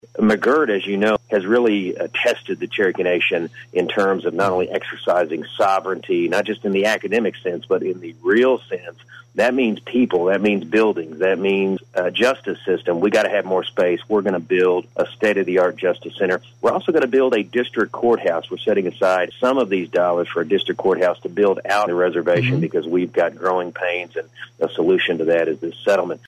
Appearing on KWON's COMMUNITY CONNECTION, Cherokee Nation Principal Chief Chuck Hoskin Jr. announced that